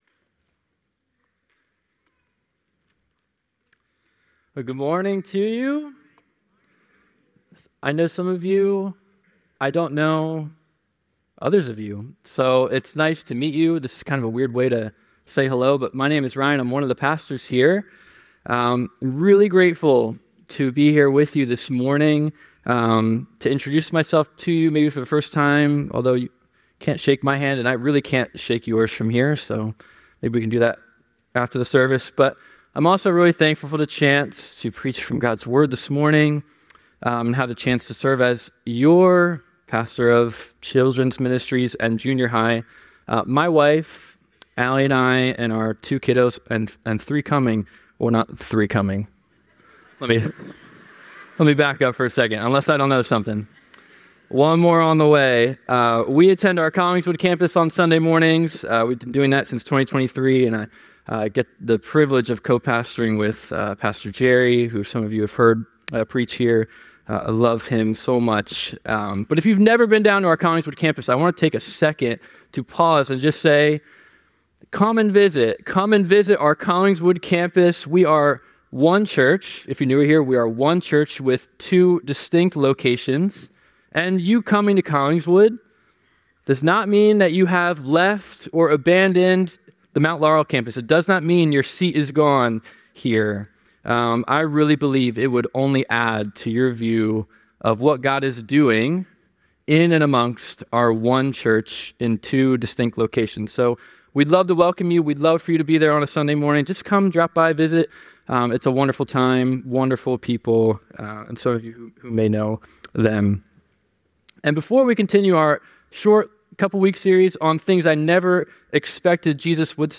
The sermon underscores the notion that all life change begins in the heart and exemplifies the grace, power, and mercy of God. He concludes by urging the congregation to embrace the transformative power of God's love.